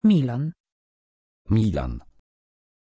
Aussprache von Milàn
pronunciation-milàn-pl.mp3